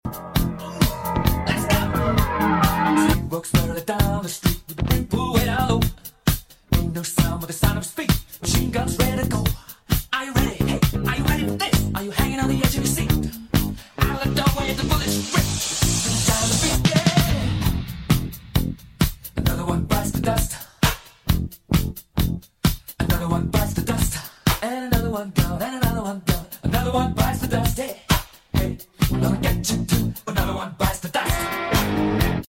AI-GENERATED style from Arkansas and Missouri RADIO! Let's Rock!